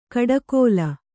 ♪ kaḍakōla